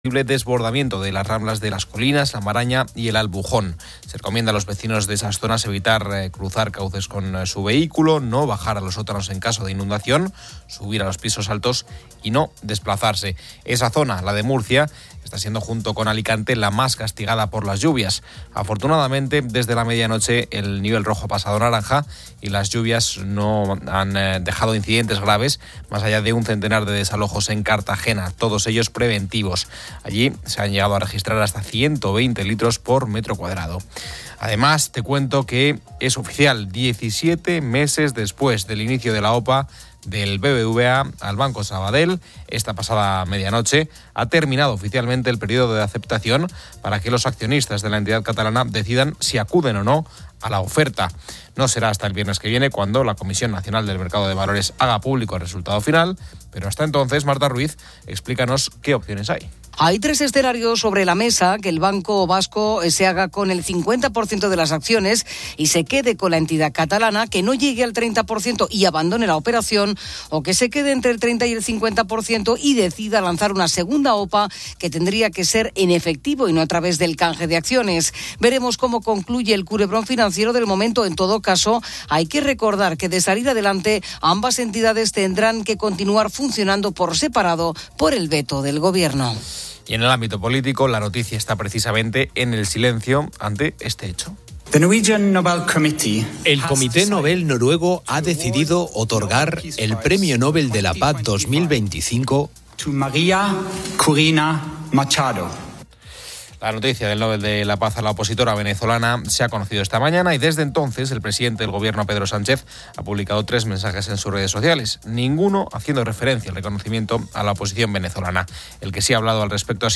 La radio COPE informa sobre diversas noticias y temas de actualidad. Se aborda la disminución de la alerta por lluvias en Murcia y Alicante tras fuertes precipitaciones, con desalojos preventivos en Cartagena.